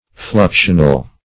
Fluxional \Flux"ion*al\, a.